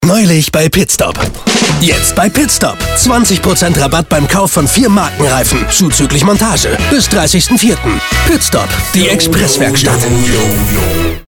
Junger, aber erfahrener Sprecher mit besonderer Stimme.
rheinisch
norddeutsch
Sprechprobe: Sonstiges (Muttersprache):